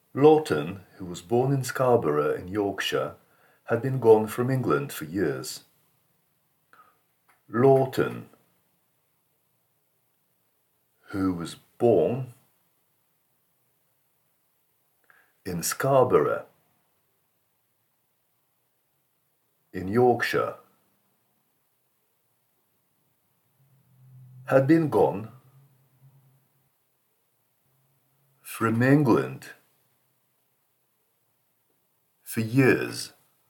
Pronunciation : the letter O :